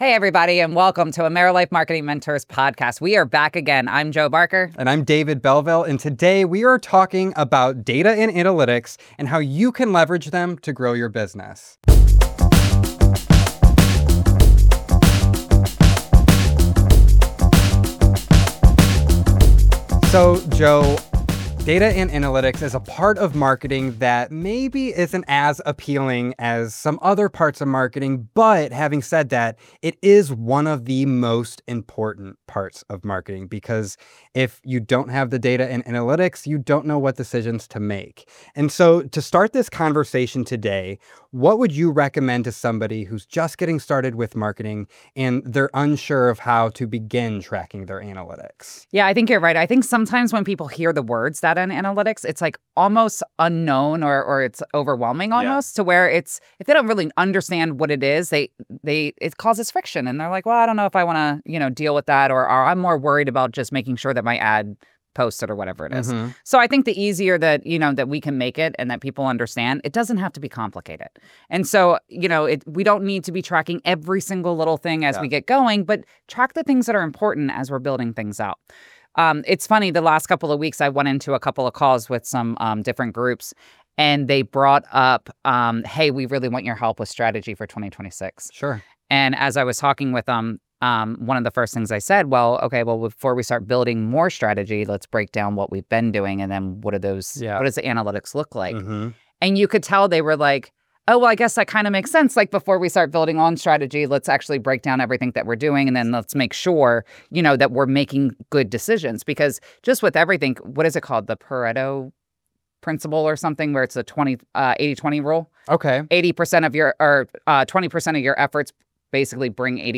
This conversation focuses on the analytics that matter, how AI is changing data interpretation, and how m